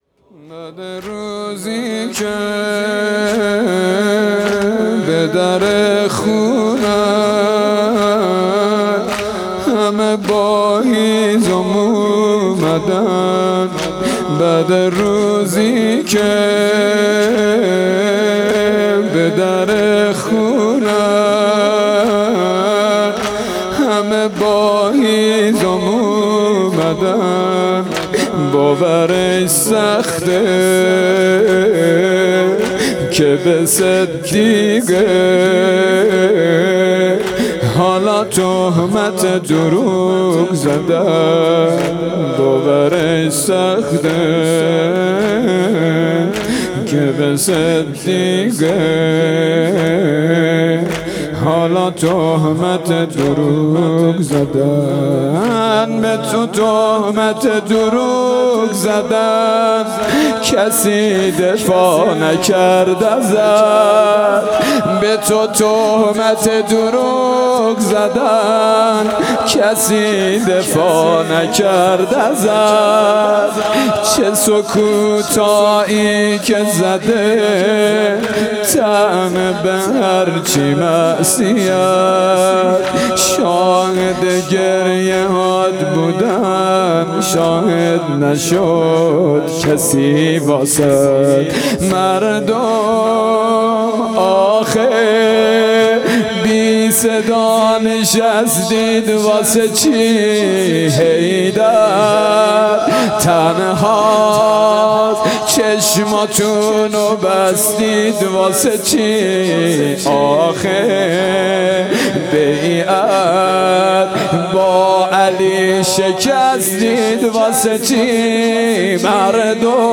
حسینیه بیت النبی - مراسم عزاداری فاطمیه اول